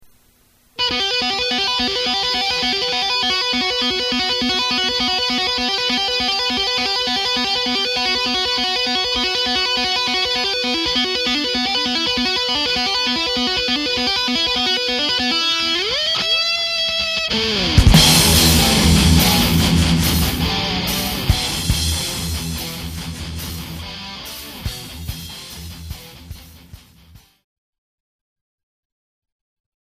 Hard`n`Heavy-Hits der 80er & 90er Jahre
energiegeladenen Sounds
Demo Songs